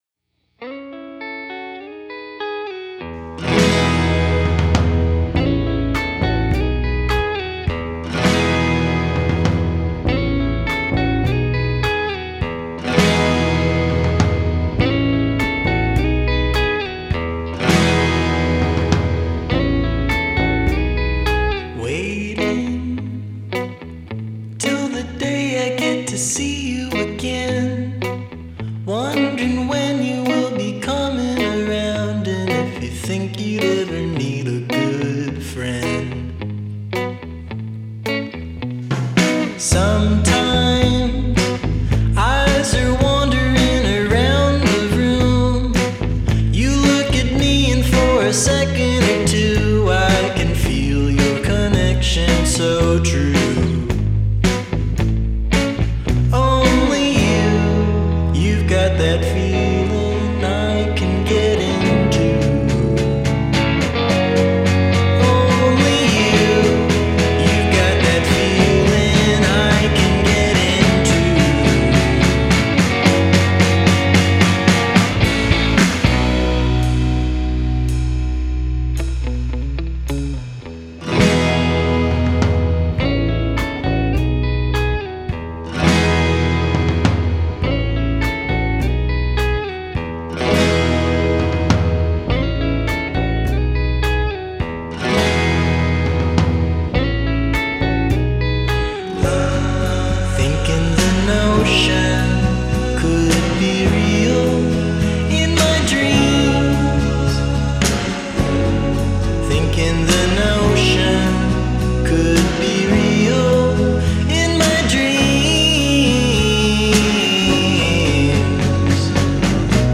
electric guitars, bass guitars, keyboards, noise, snare